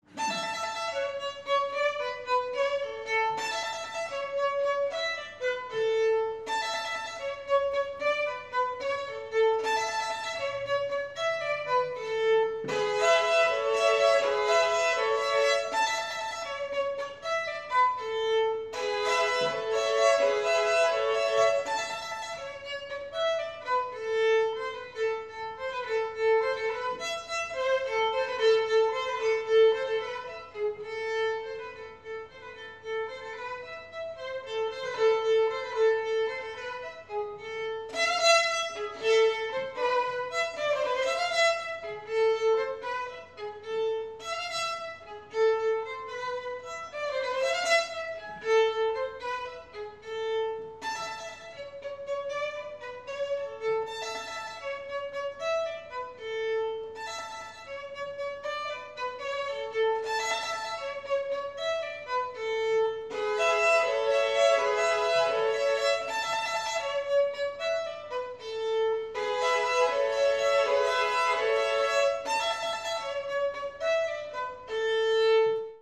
Workshop Recording of a Selection from the 1808 Set.
(I announce the titles as I play through)
Live workshop recordings from SOUNDBOX at the Royal Academy of Music Museum 29th May 2012
Peter Sheppard Skaerved-Violin
This reel has some rather snazzy syncopation, which seems to demand some stamping….